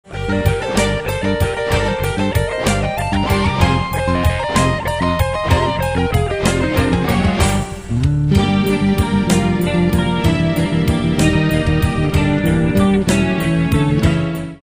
métal progressif